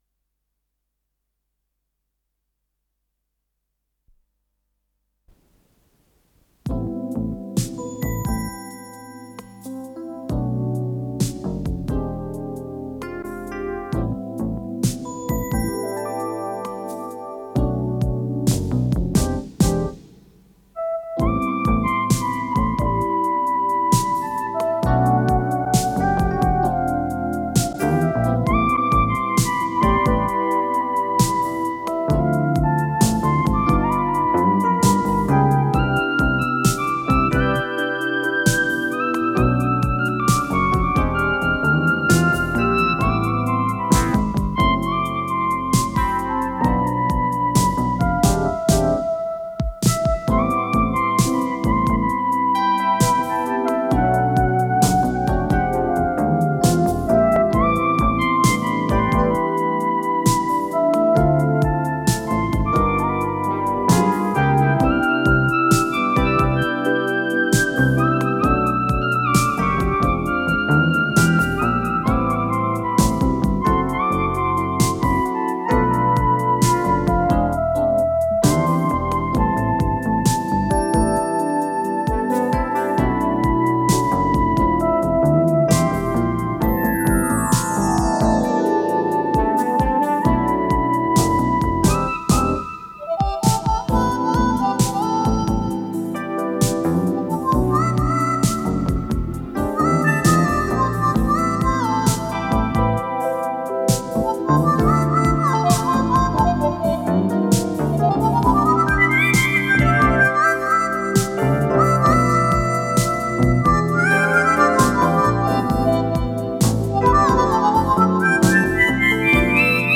РедакцияМузыкальная
синтезатор
ВариантДубль моно